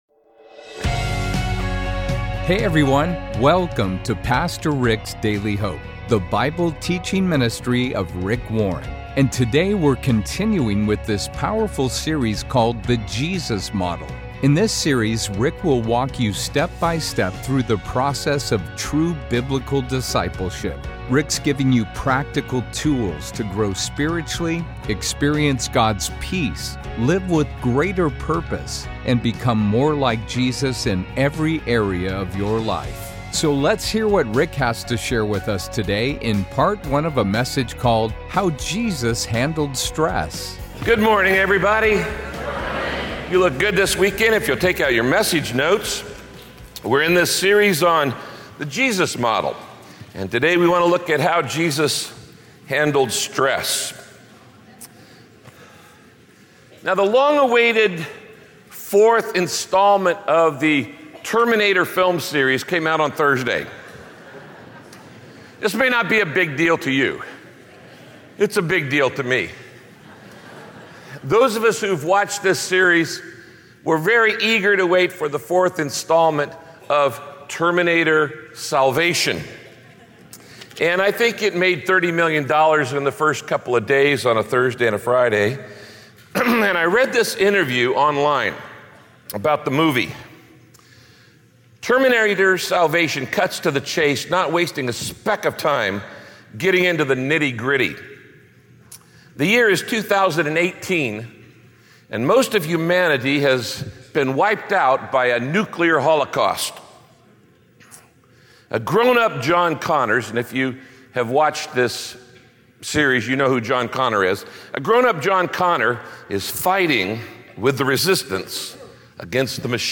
If you don't know who you are, then your confused identity is always going to cause you stress. Pastor Rick teaches in this message how to avoid stress by being…